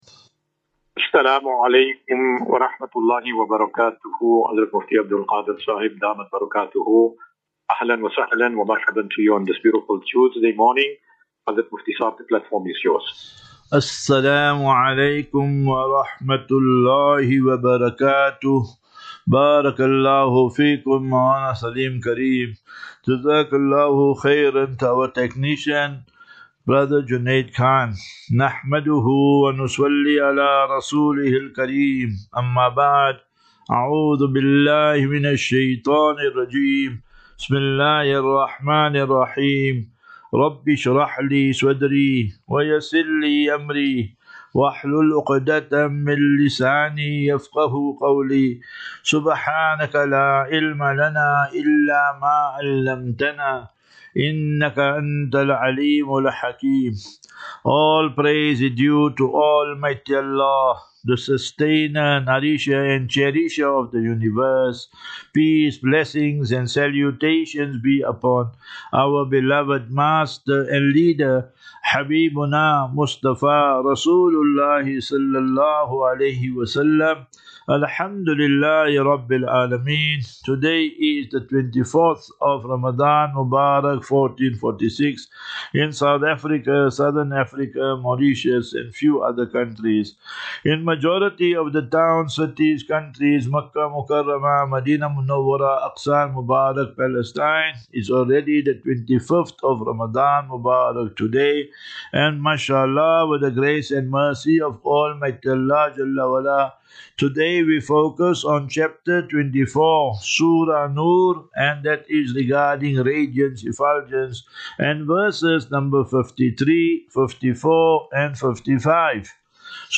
View Promo Continue Install As Safinatu Ilal Jannah Naseeha and Q and A 25 Mar 25 March 2025.